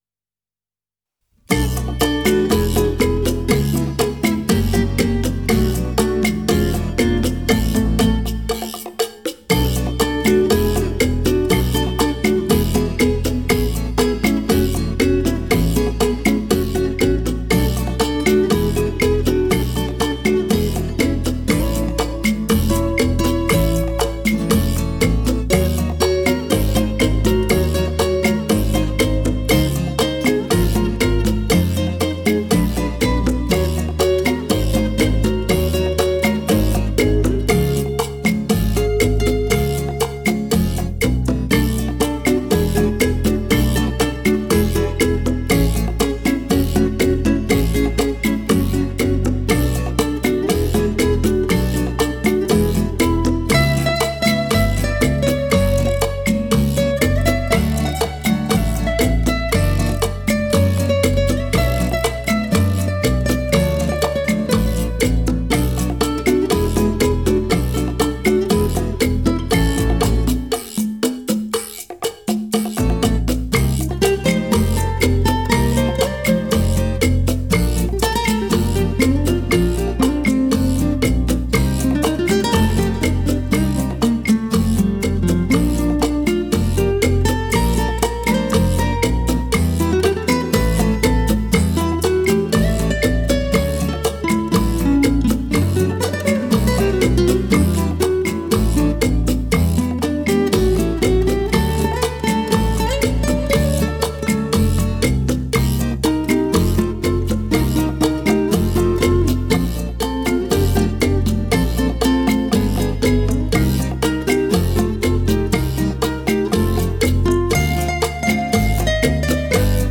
Genre:Latin, Pop
Style:Salsa,Easy Listening